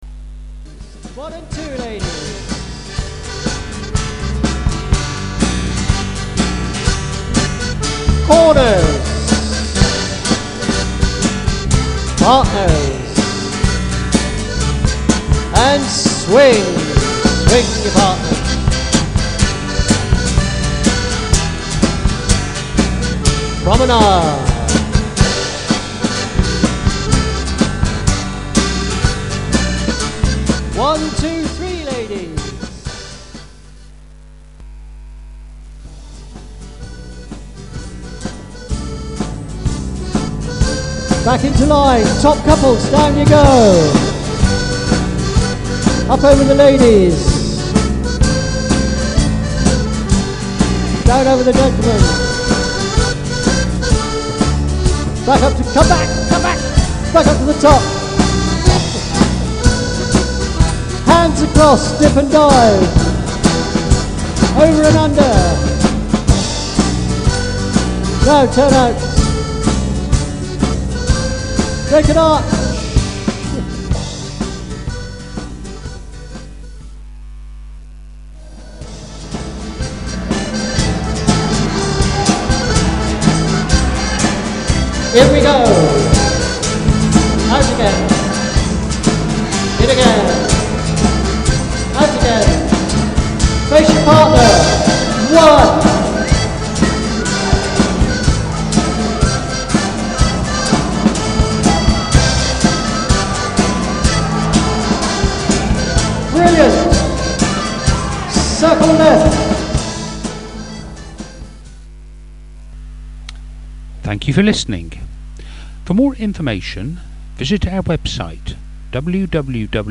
From £865 + travel | Ceilidh Band